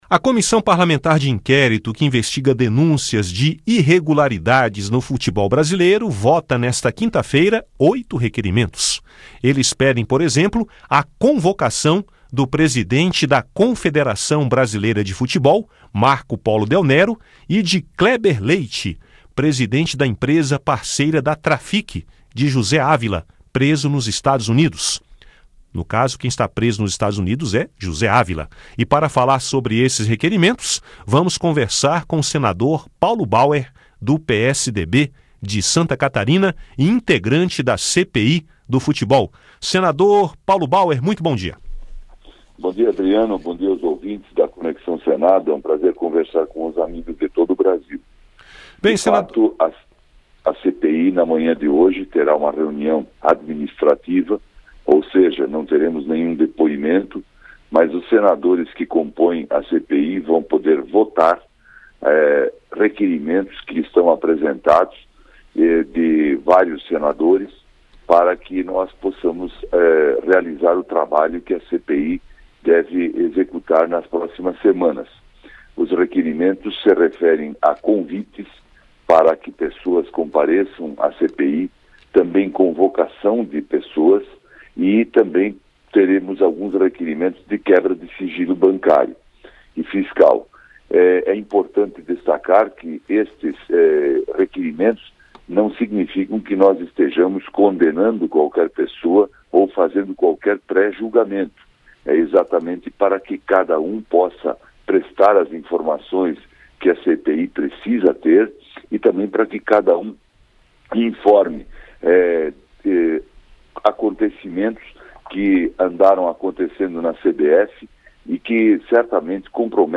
Entrevista com o senador Paulo Bauer (PSDB-SC), vice-presidente da CPI do Futebol.